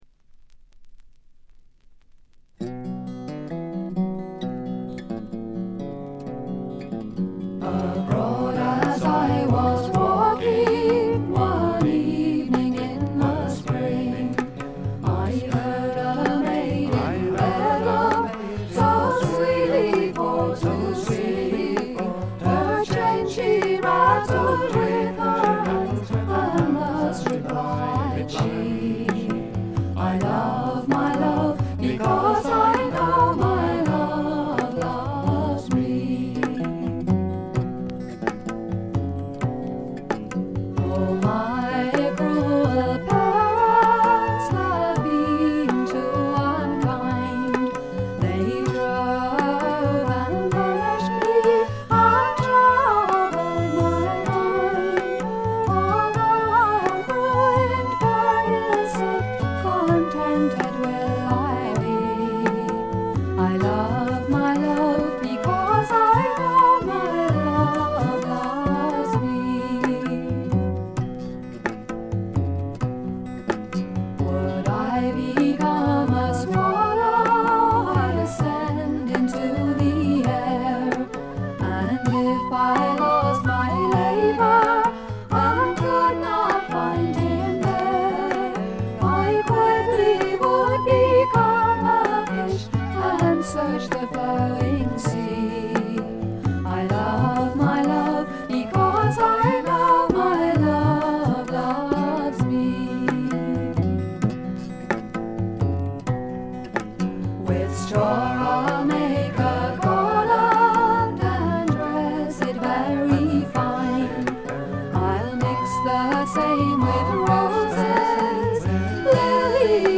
バックグラウンドノイズや軽微なチリプチは普通レベルで出ますが特筆するようなノイズはありません。
また専任のタブラ奏者がいるのも驚きで、全編に鳴り響くタブラの音色が得も言われぬ独特の味わいを醸しだしています。
試聴曲は現品からの取り込み音源です。